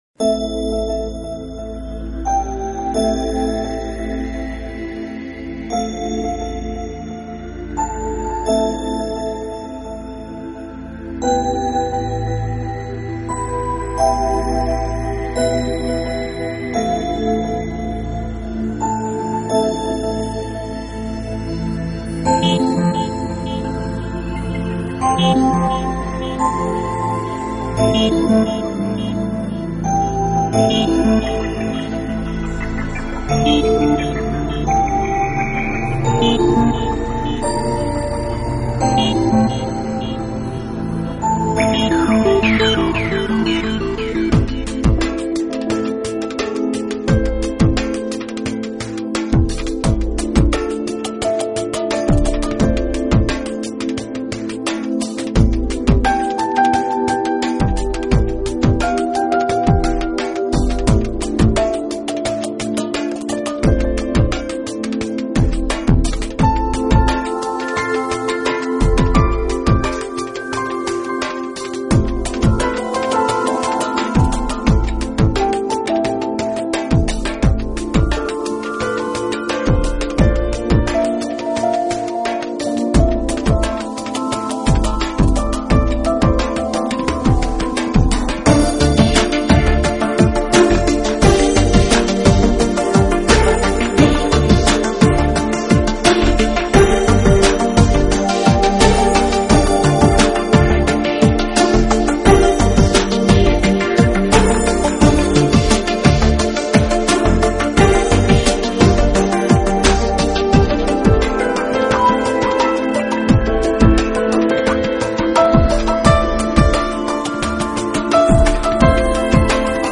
音乐流派： New Age